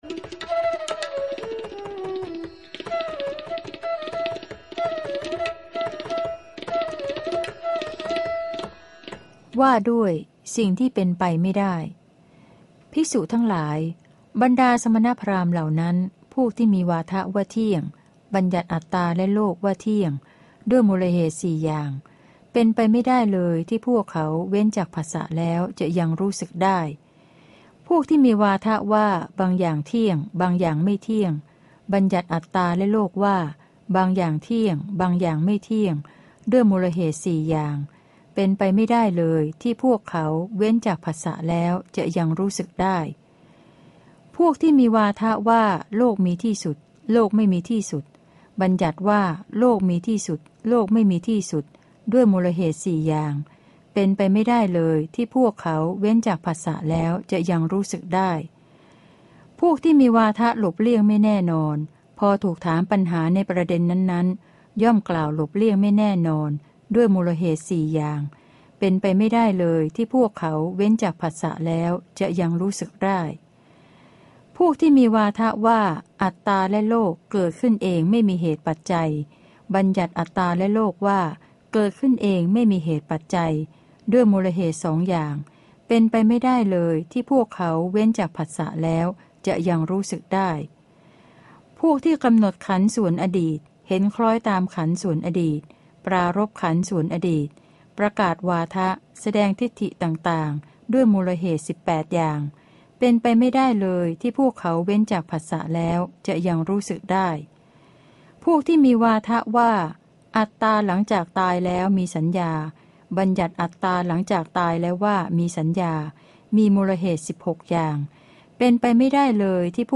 พระไตรปิฎก ภาคเสียงอ่าน ฉบับมหาจุฬาลงกรณราชวิทยาลัย - เล่มที่ ๙ พระสุตตันตปิฏก